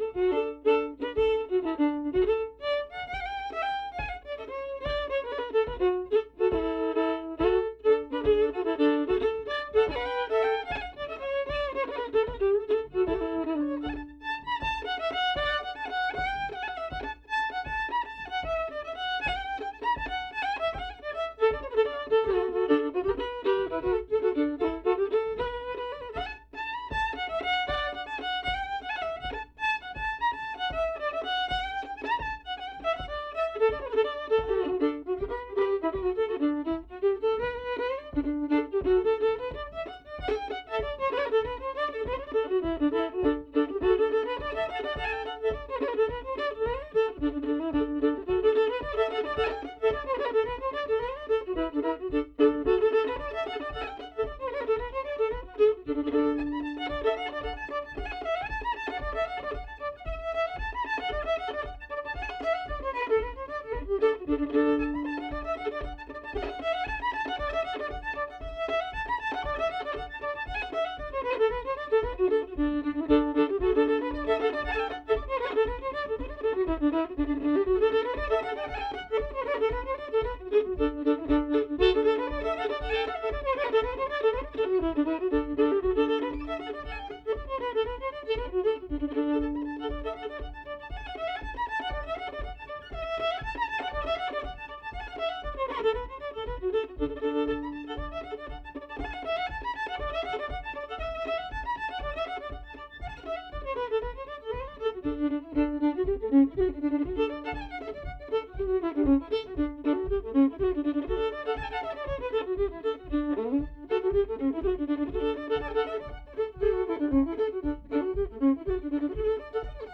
Note Accompanied by: unacc